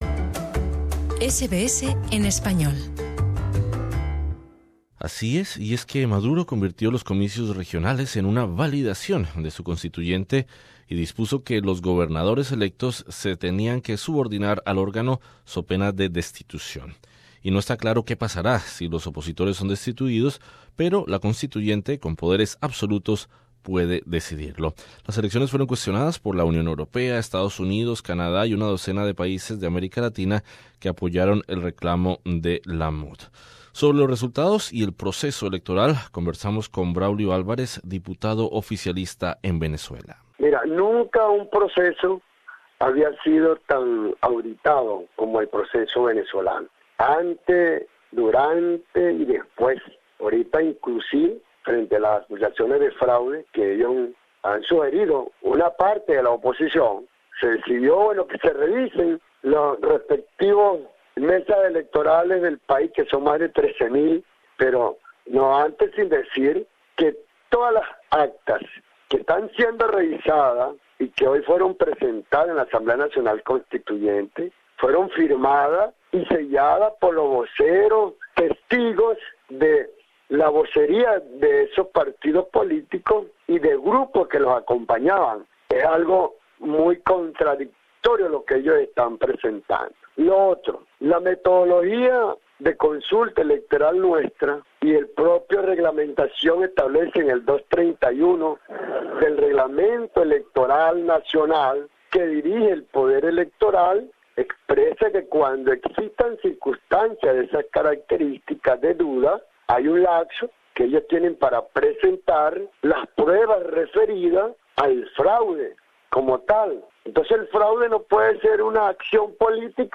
Entrevista con Braulio Alvarez